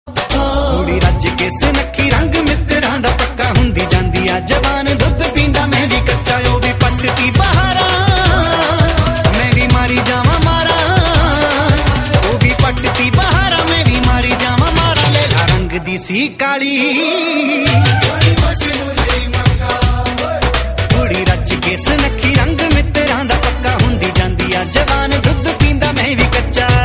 The Whistle